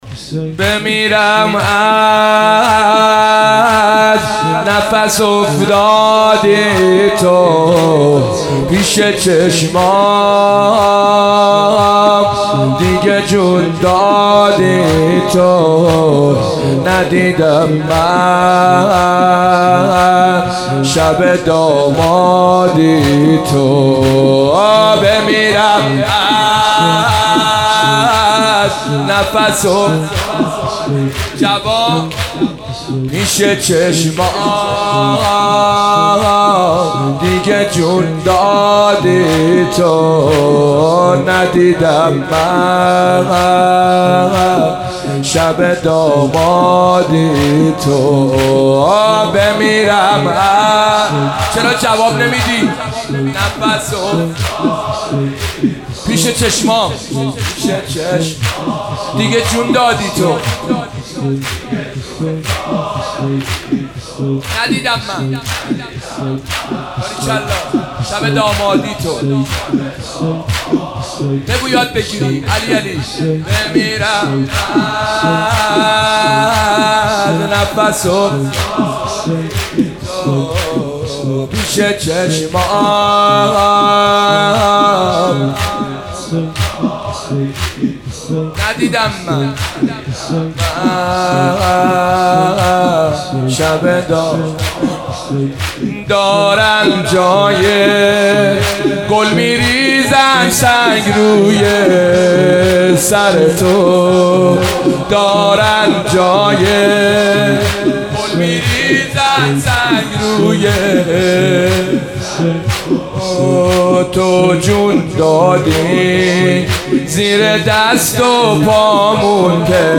مداح
مناسبت : شب ششم محرم
قالب : زمینه